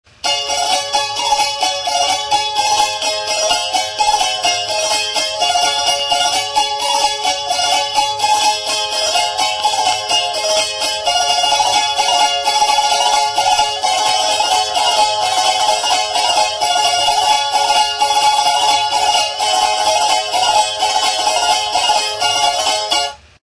Lau sokazko cavaquinho arrunta da. Soka metalikoak ditu.